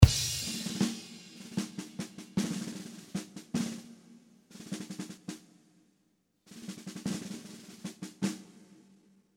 Classic rock rhythm in 75 bpm.
Big bass drum and long reverb on the snare promise to give you
The right feeling in ballads rock style.
Qty: $5.00 Classic rock rhythm in 77 bpm.